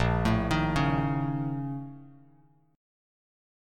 A#mM7bb5 Chord